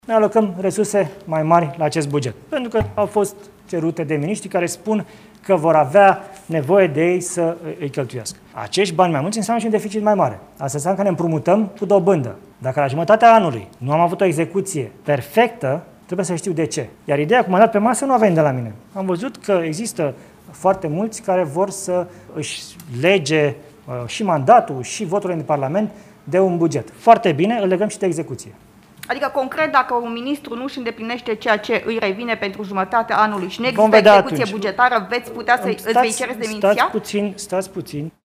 Premierul a lansat acest avertisment în cadrul unei conferințe de presă așa că jurnaliștii l-au rugat să explice dacă astfel, anunță o remeniere.